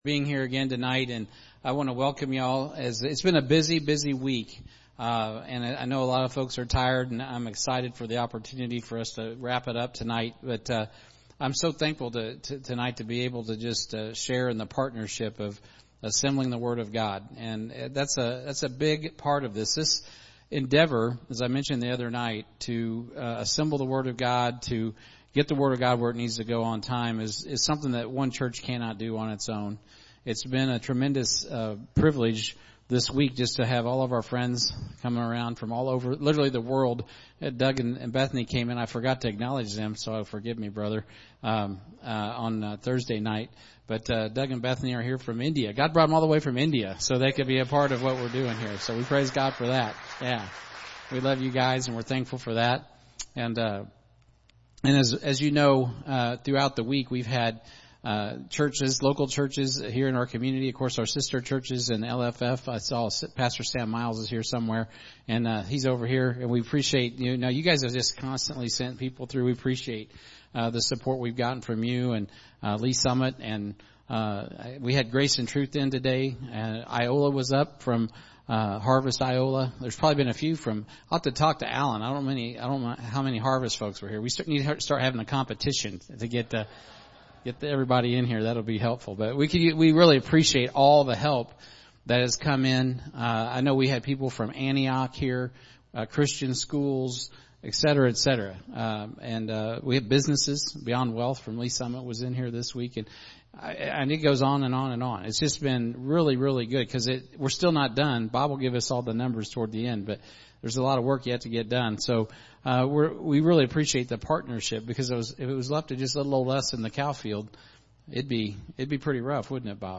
Sermons | Heartland Baptist Fellowship
The Bible Conference